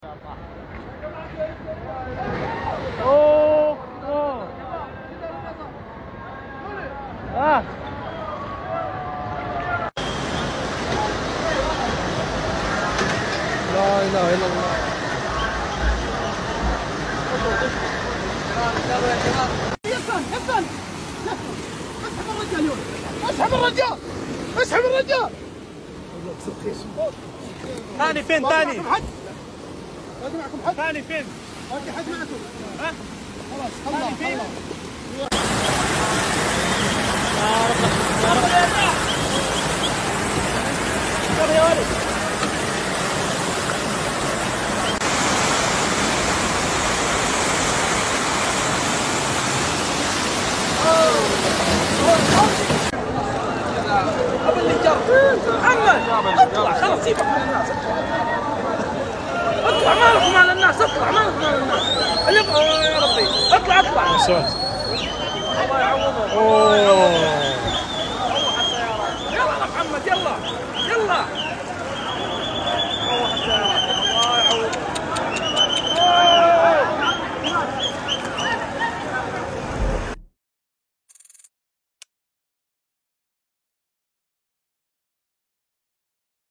سيول عارمة تجتاح مدينة عسير Sound Effects Free Download